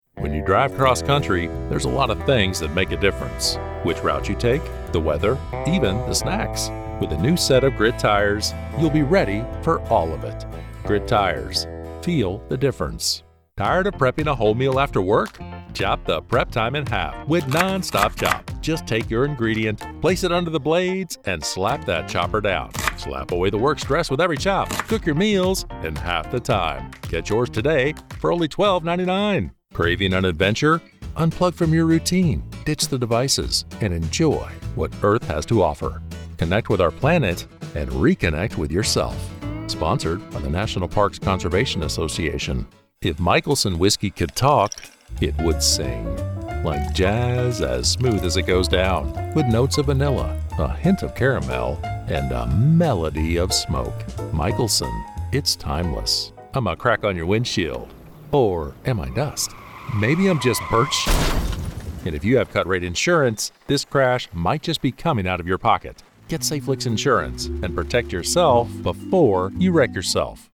0618Commercial_Demo.mp3